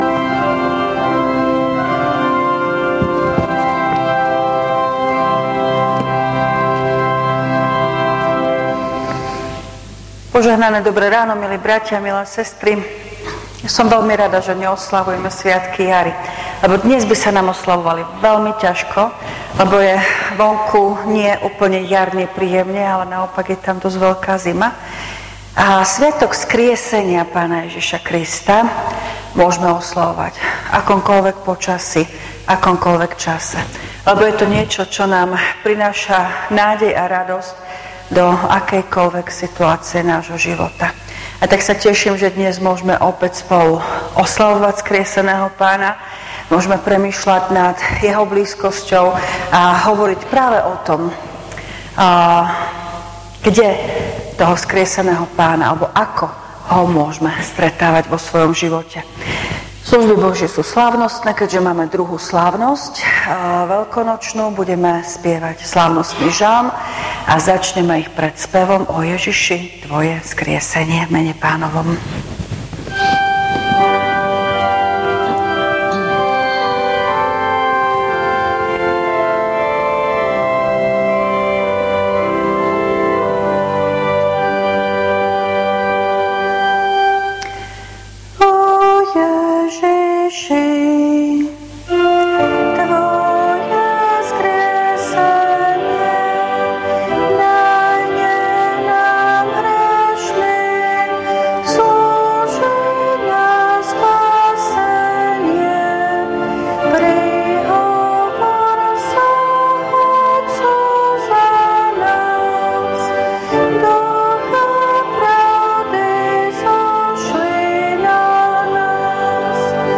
V nasledovnom článku si môžete vypočuť zvukový záznam zo služieb Božích – 2. slávnosť veľkonočná.